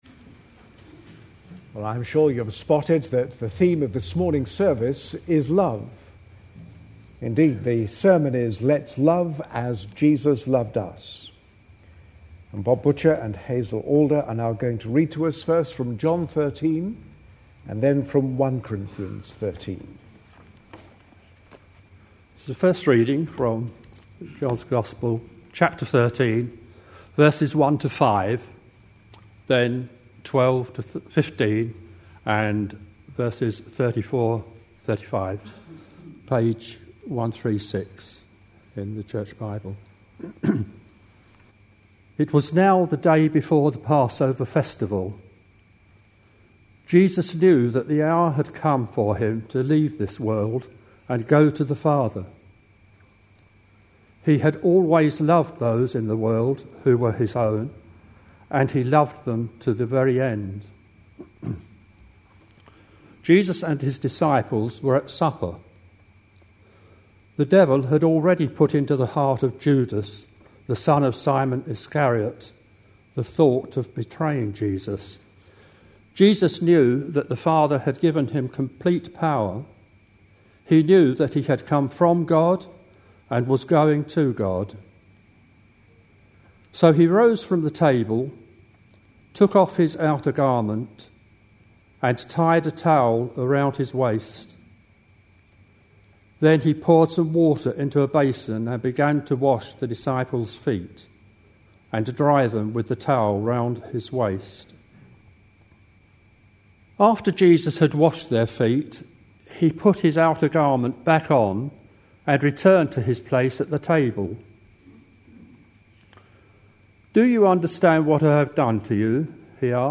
A sermon preached on 13th February, 2011, as part of our A Passion For.... series.